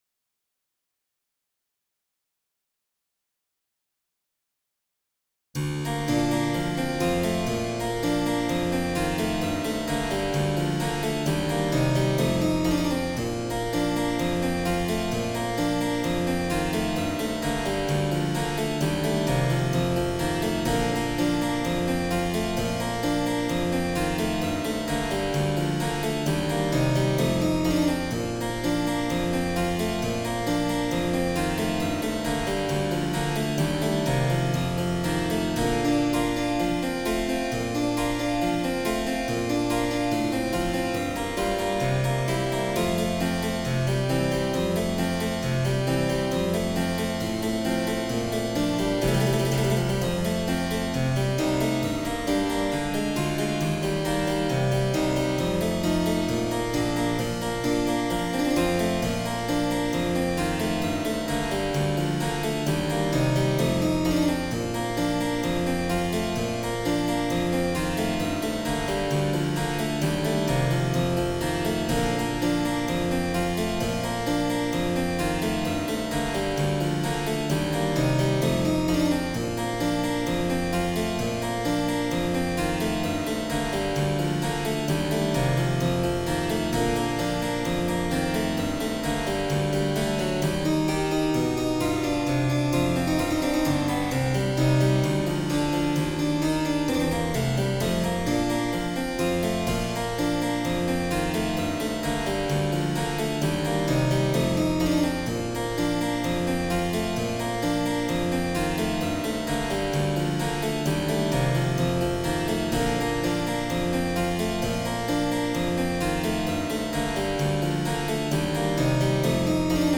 Music for flauto dolce / mandolino and cembalino d'amore by Lully, Couperin, Rameau and Edelmann (Please read the score first, before you start)